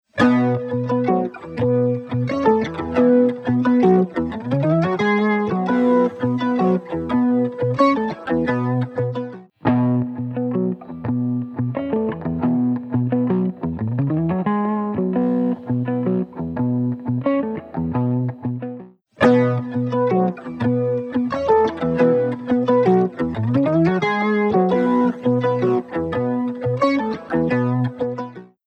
Octavox | Electric Guitar | Preset: 12 String
Octavox-Eventide-Clean-Guitar-12-String.mp3